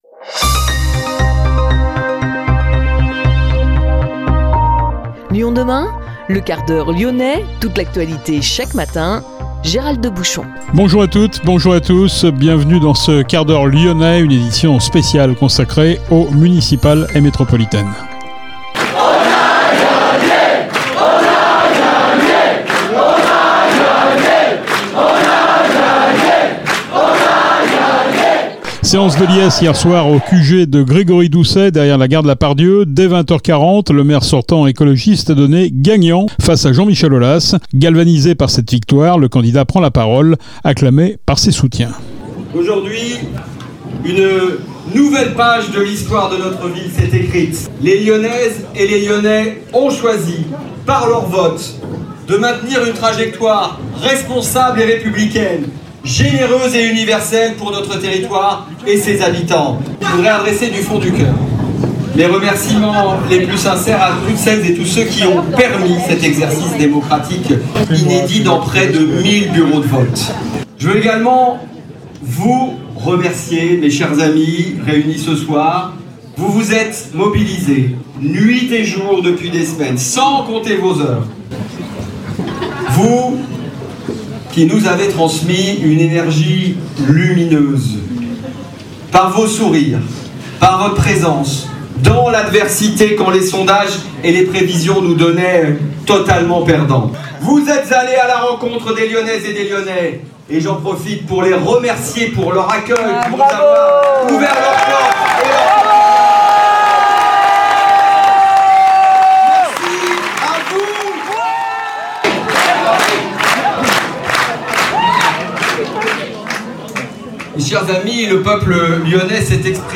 Scènes de liesse hier soir au QG de Grégory Doucet, derrière la gare de la Part-Dieu. Dès 20h40, le maire sortant écologiste est donné gagnant face à Jean-Michel Aulas.